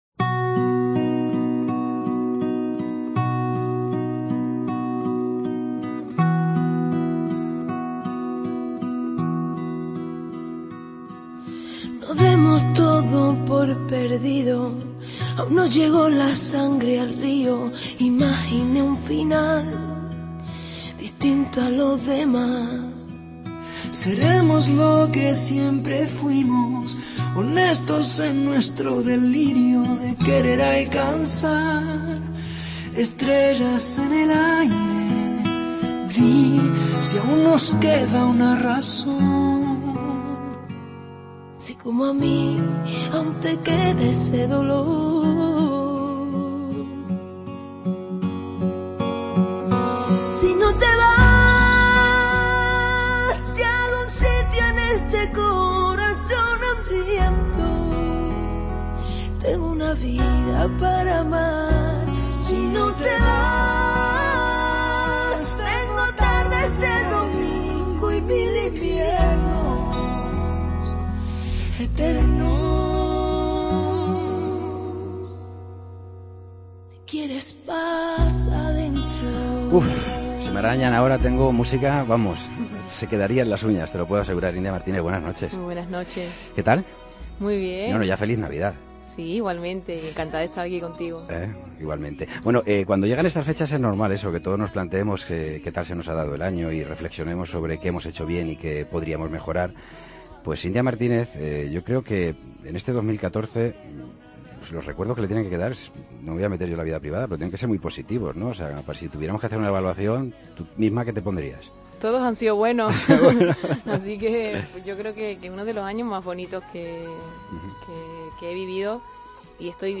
Nos interpreta un villancico